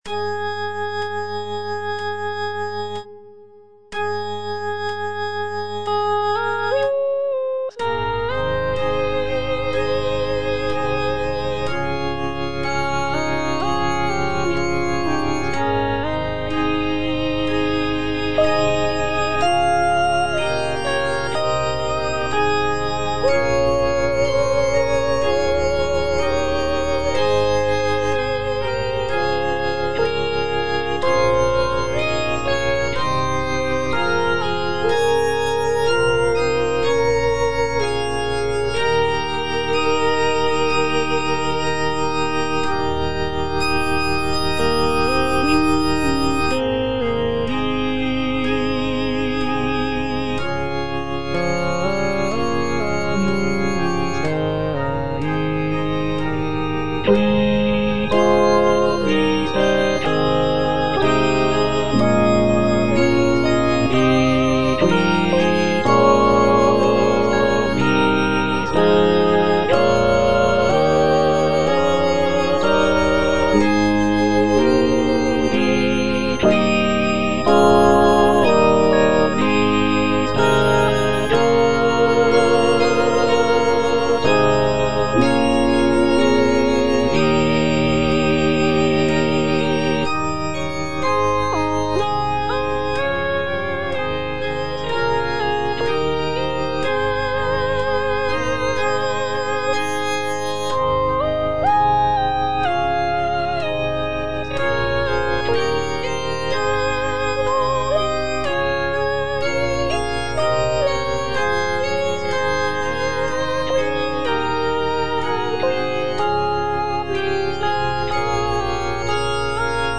Tenor (Voice with metronome) Ads stop
is a sacred choral work rooted in his Christian faith.